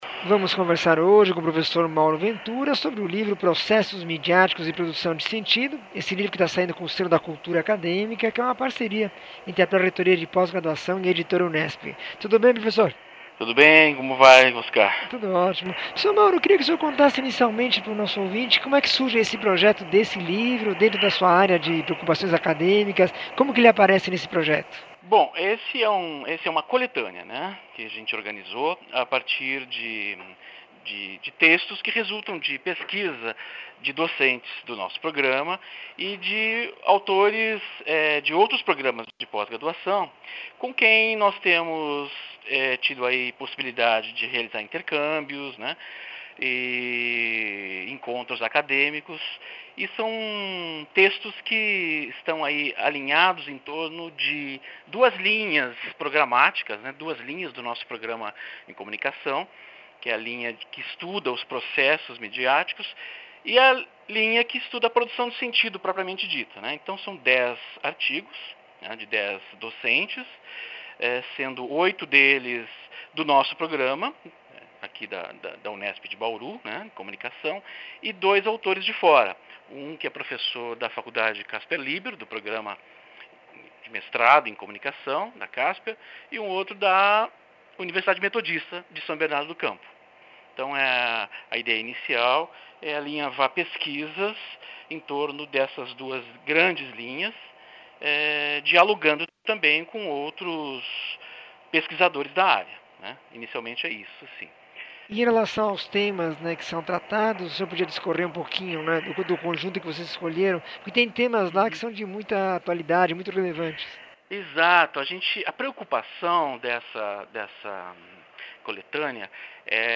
entrevista 1421